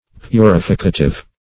Meaning of purificative. purificative synonyms, pronunciation, spelling and more from Free Dictionary.
purificative.mp3